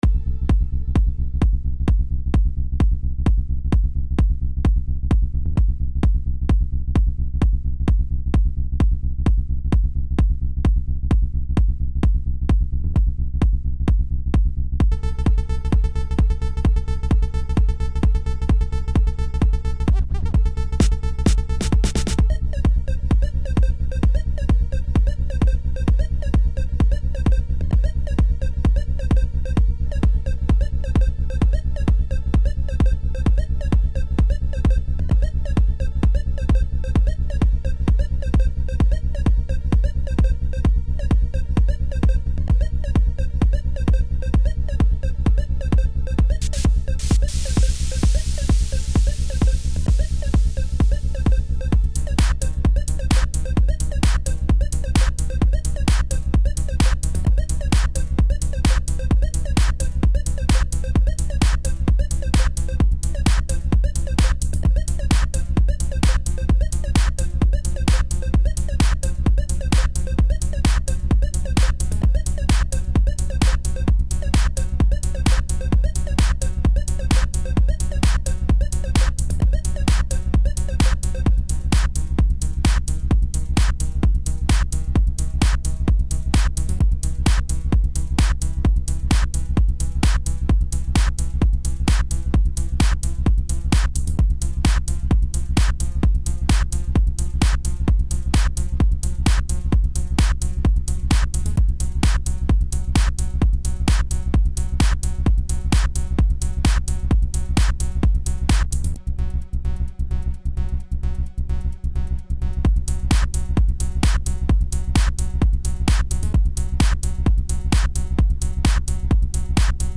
Скачать Минус
Стиль: Techno